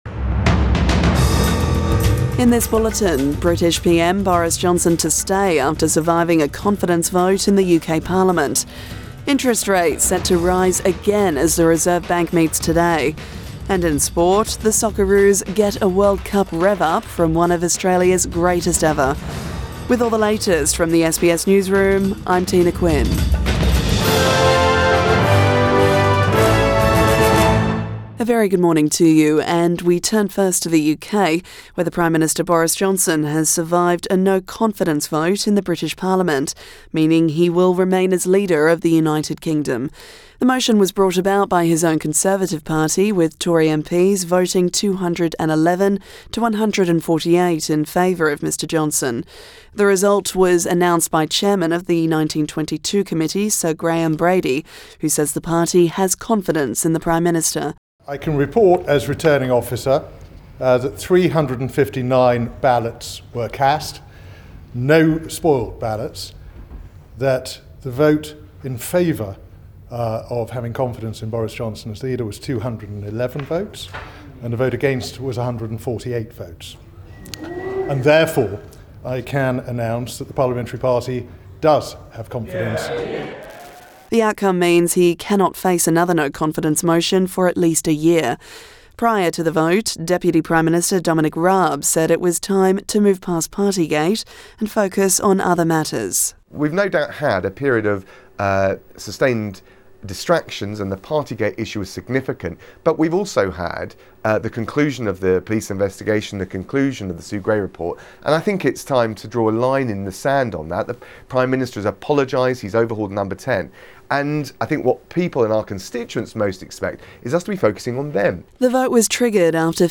AM bulletin 7 June 2022